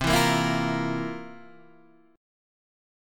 C11 Chord
Listen to C11 strummed